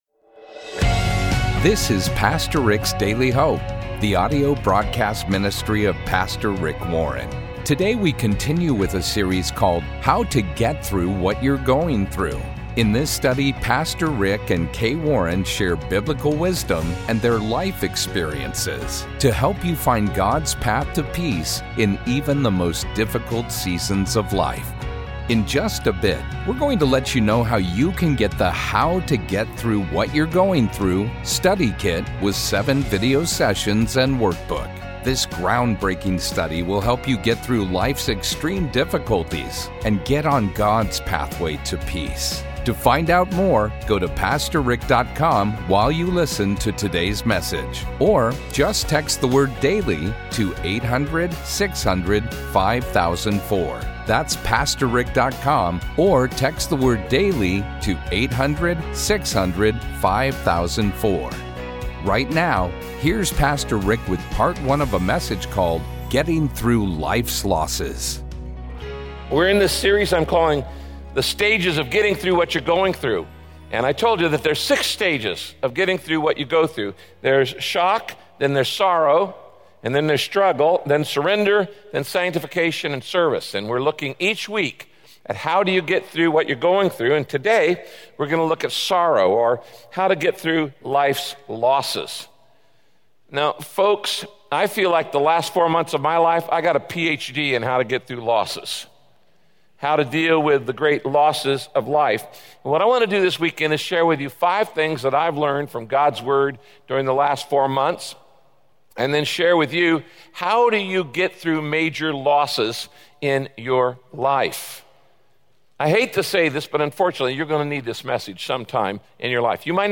My Sentiment & Notes Sorrow: Getting Through Life’s Losses - Part 1 Podcast: Pastor Rick's Daily Hope Published On: Tue Jul 11 2023 Description: In this biblical message, Pastor Rick explains why grief is a necessary and healthy response to loss, and how avoiding it can keep you from moving forward in life.